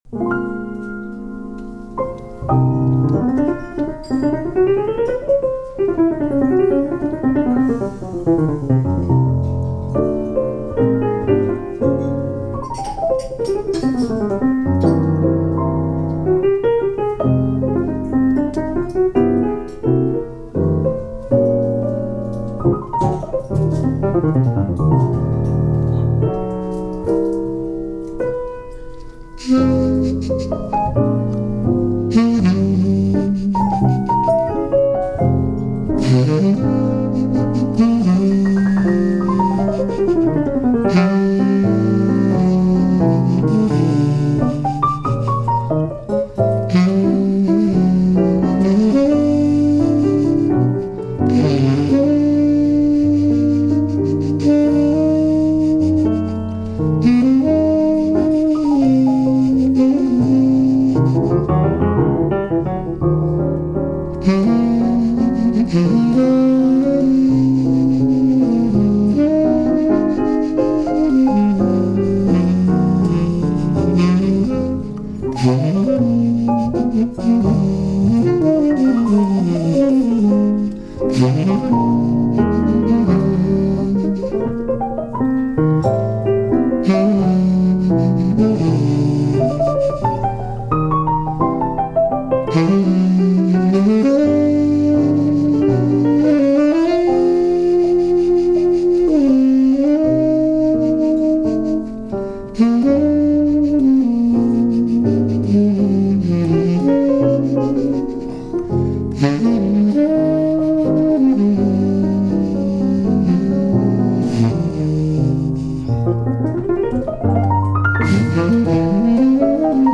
piano
clarinet